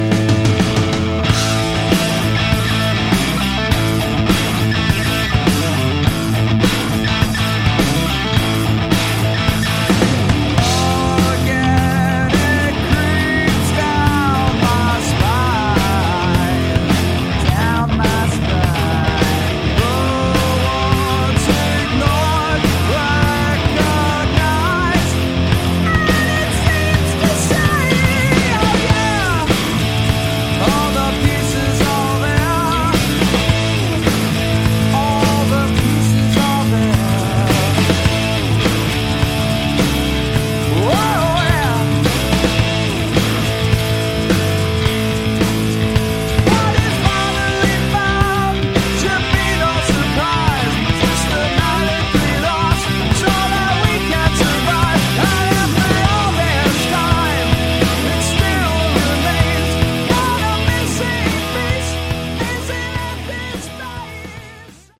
Category: Hard Rock
vocals
guitar, backing vocals
bass, backing vocals
drums